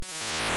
The sound effect that plays when some enemies are defeated in Super Mario Land
SML_enemy_death_sound_effect.wav.mp3